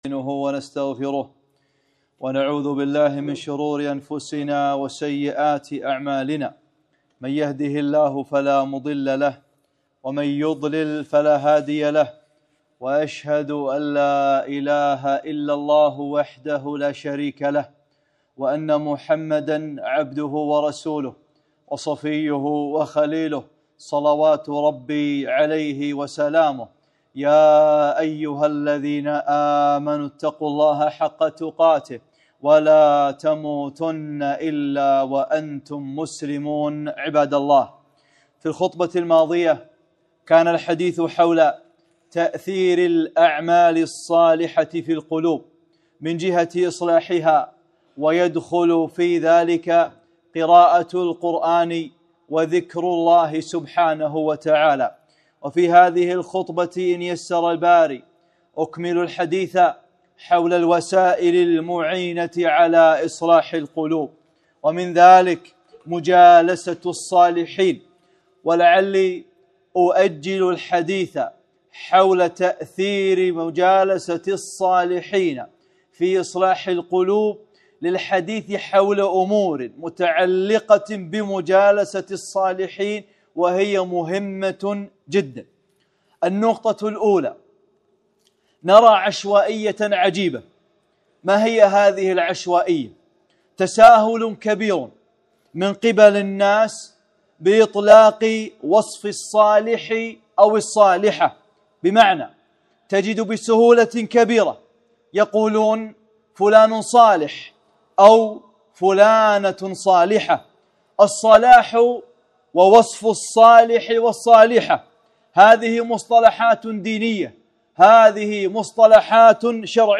(4) خطبة - من هو الصالح؟ | سلسلة أعمال القلوب